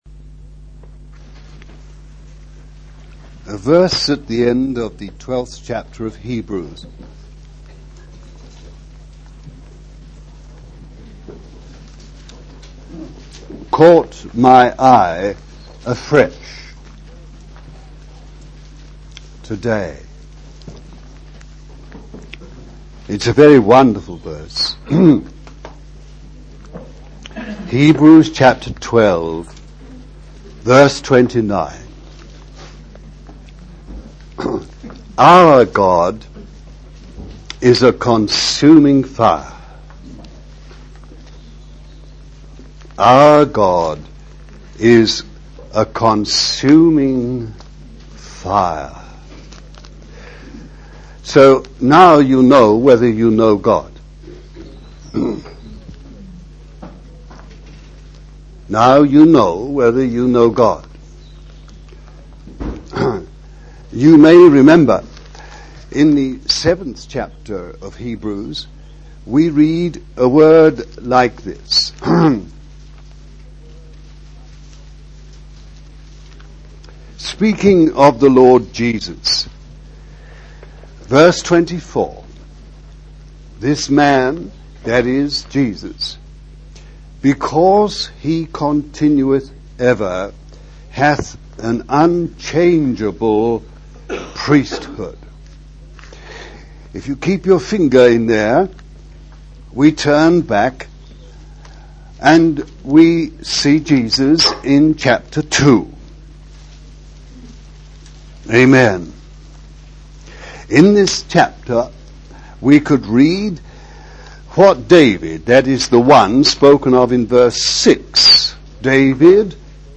No 1 of 6 messages on the Fire of God, given over a period of 4 years at Rora House CF, Devon; Devonshire Rd CF Liverpool; the Longcroft CF, Wirral